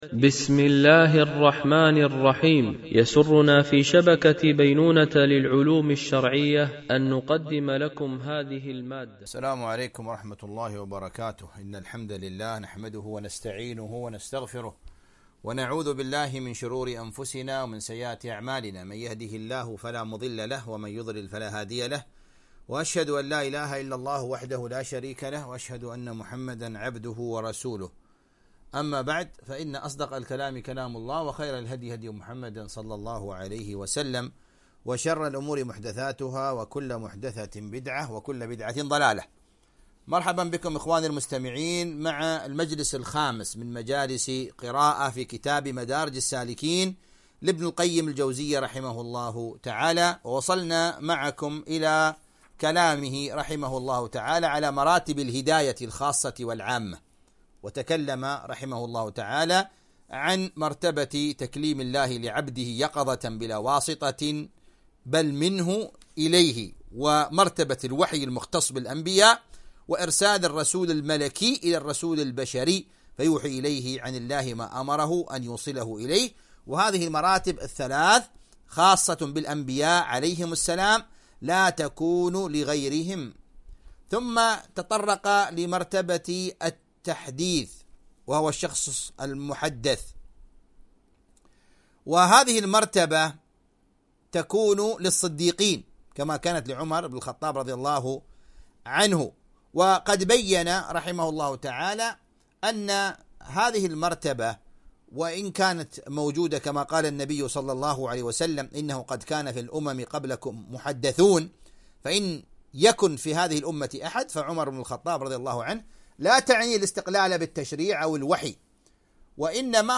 قراءة من كتاب مدارج السالكين - الدرس 05
MP3 Mono 44kHz 64Kbps (CBR)